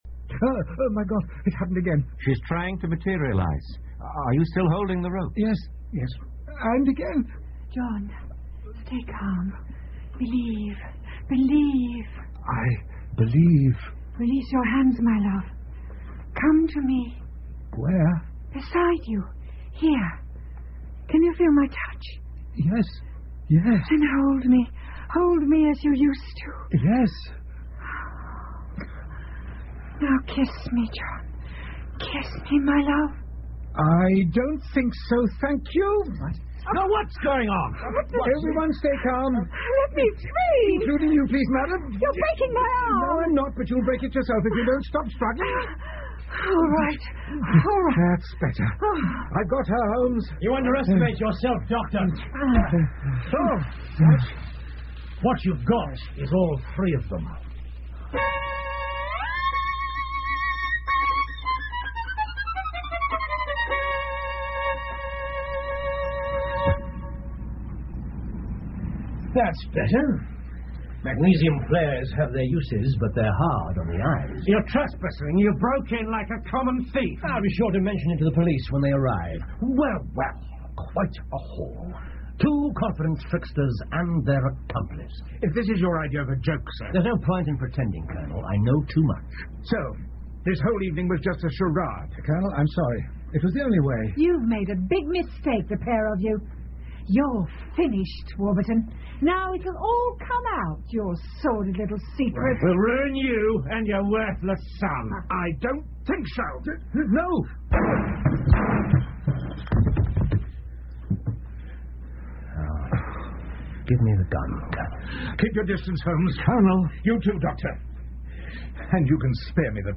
福尔摩斯广播剧 Futher Adventures-The Madness Of Colonel Warburton 8 听力文件下载—在线英语听力室